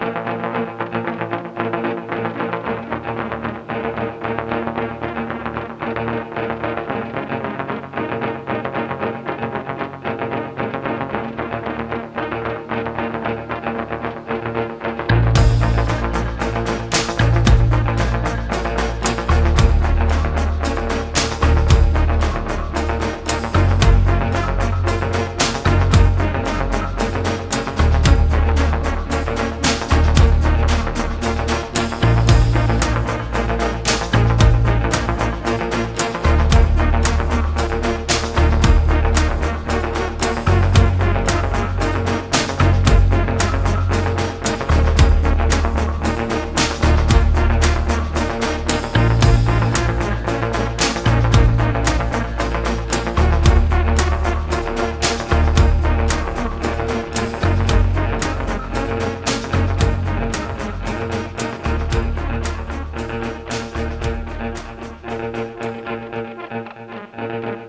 gunstore.wav